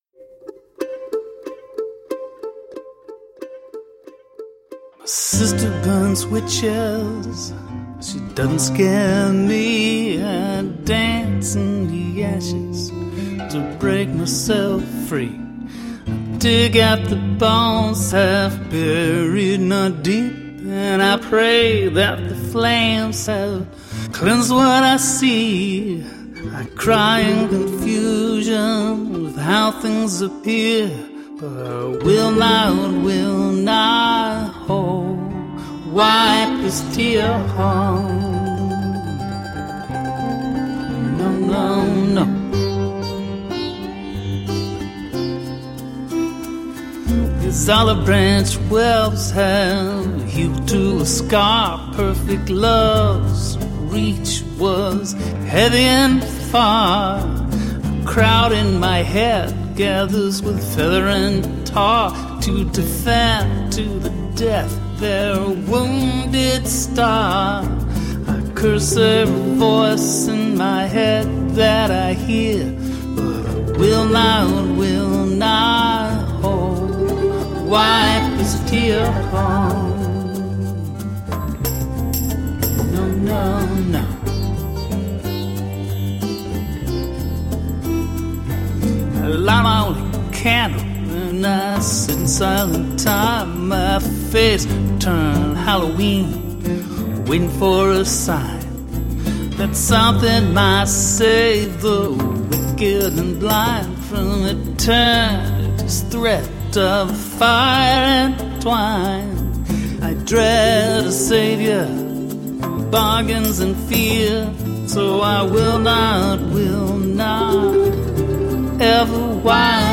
singer songwriter
Tagged as: Alt Rock, Rock, Country, Folk